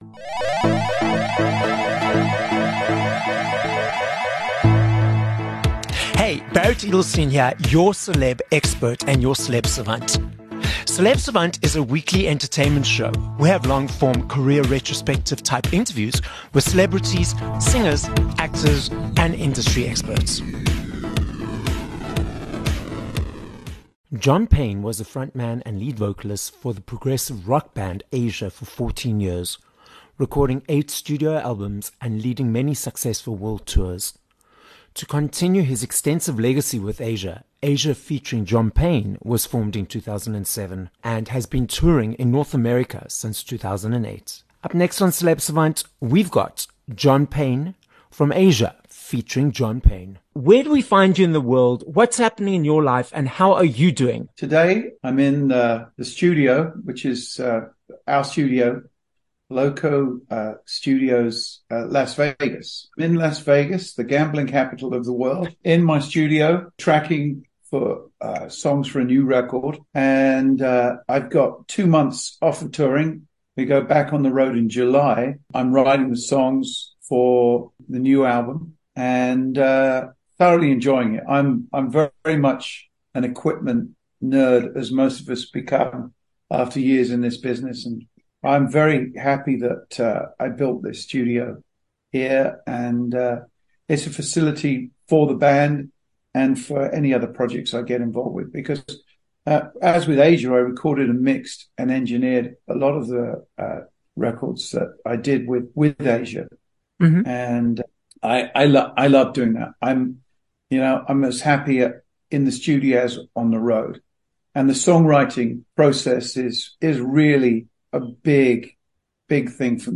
1 Jun Interview with John Payne (Asia ft John Payne)
An “equipment nerd” is how British-born, American-based rock singer and songwriter, John Payne from Asia featuring John Payne, describes himself.